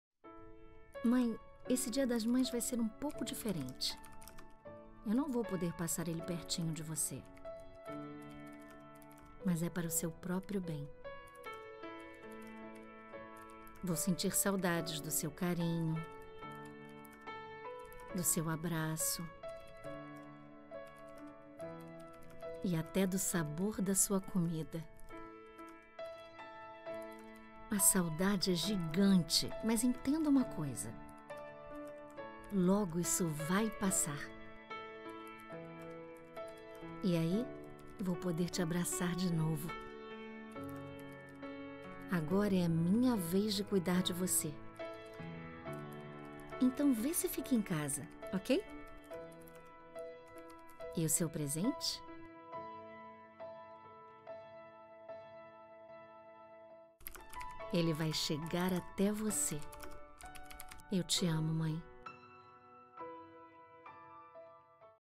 Feminino
Voz Jovem 01:05